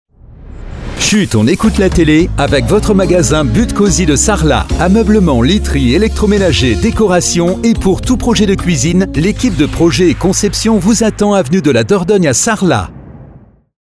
et voici le spot de votre But Cosy de Sarlat